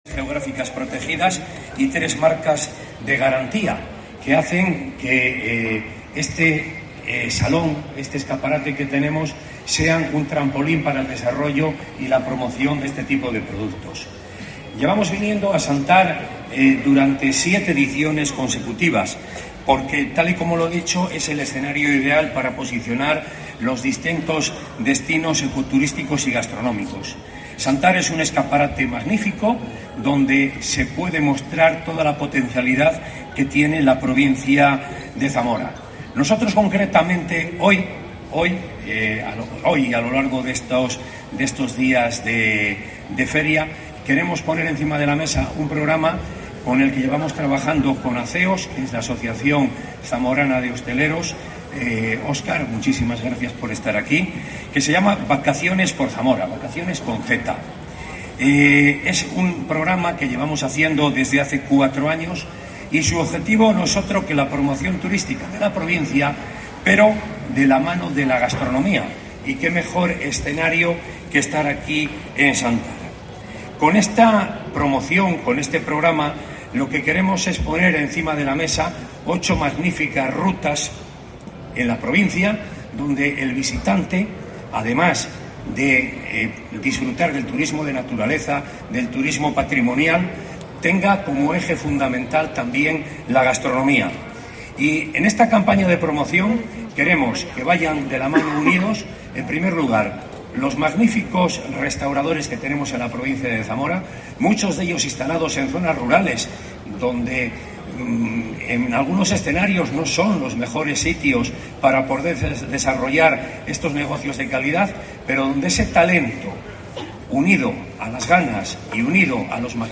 Javier Faúndez, presidente de la Diputación de Zamora en Xantar